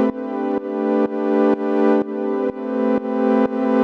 GnS_Pad-dbx1:4_125-A.wav